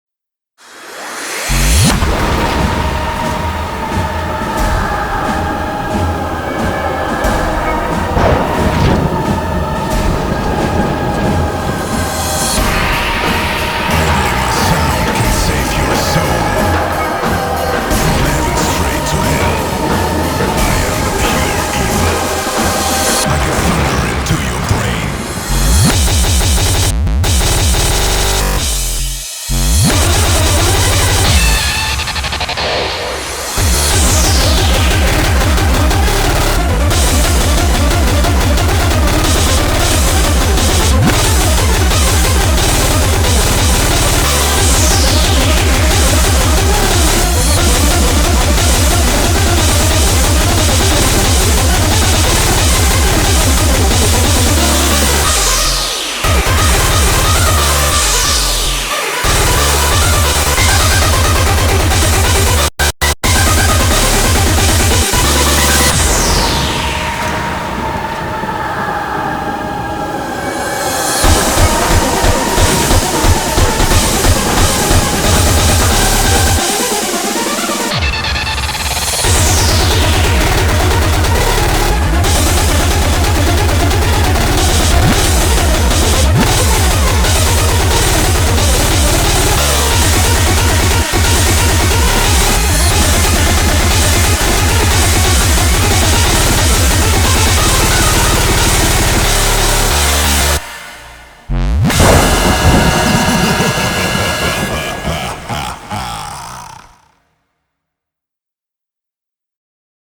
BPM90-360
Audio QualityPerfect (High Quality)
Comments[DECEPTIVE SPEEDCORE]